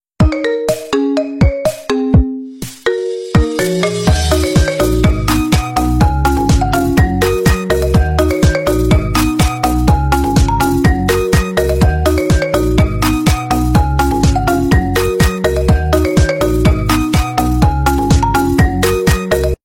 pkmn_alarm.mp3